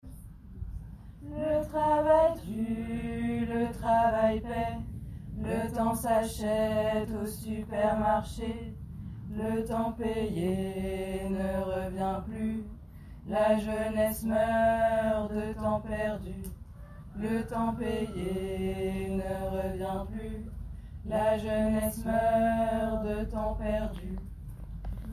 Voix basse :